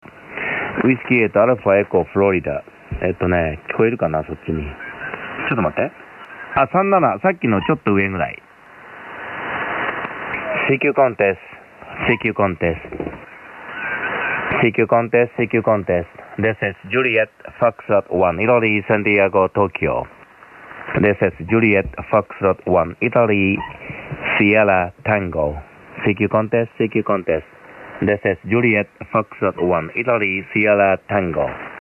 Sample Hi‑Fi SSB Audio
Rx:FT DX 9000D